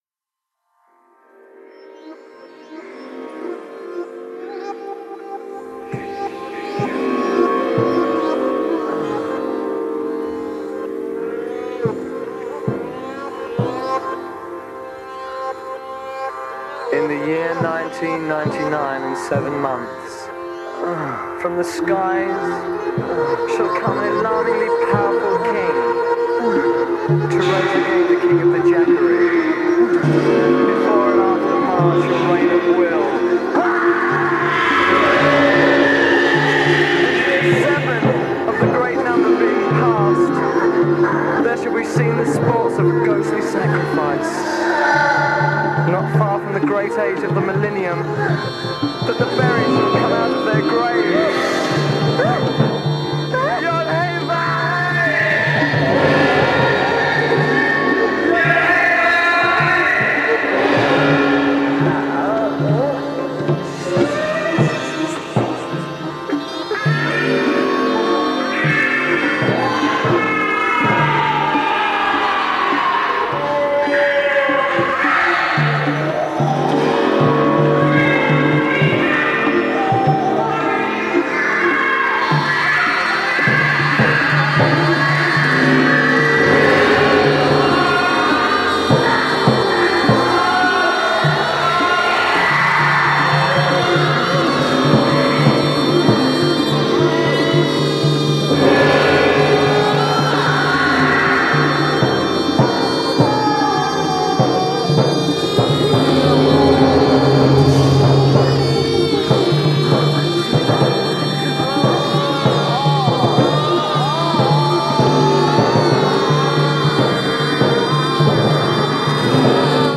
1970年に生まれたサイケデリック・ロックの金字塔！